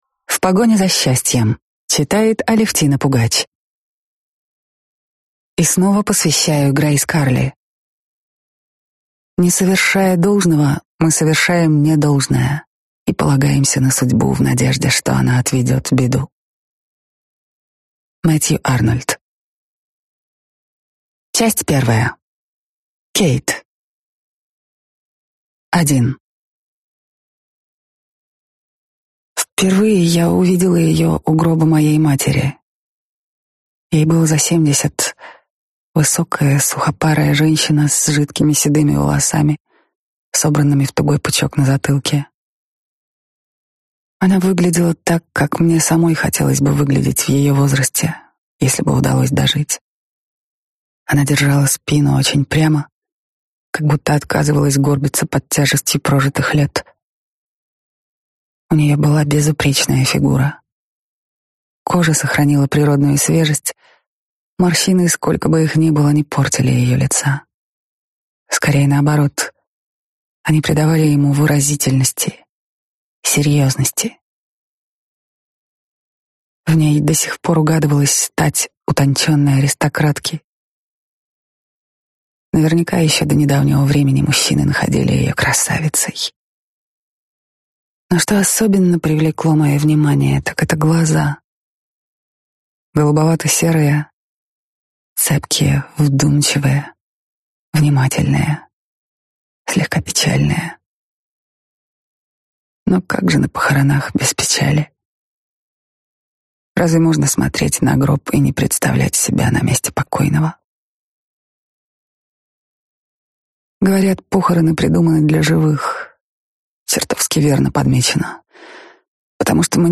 Аудиокнига В погоне за счастьем | Библиотека аудиокниг